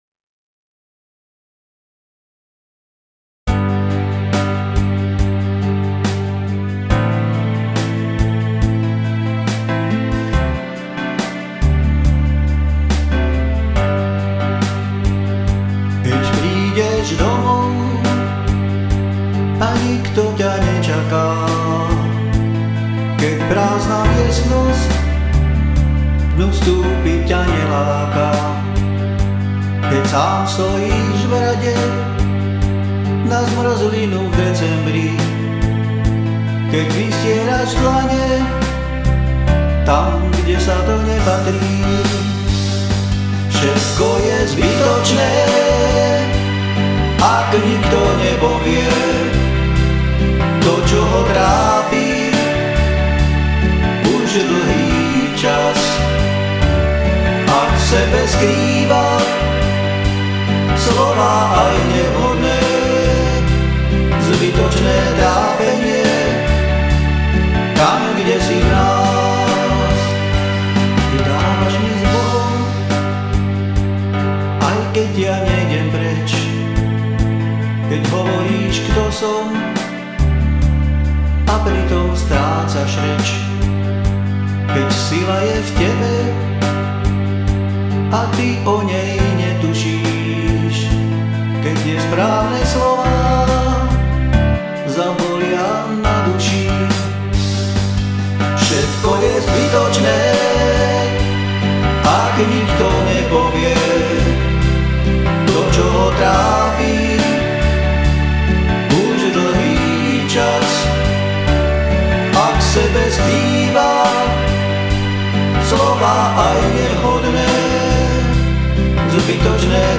Som amatérsky muzikant, skladám piesne väčšinou v "záhoráčtine" a tu ich budem prezentovať.